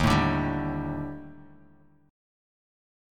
D#M7b5 Chord
Listen to D#M7b5 strummed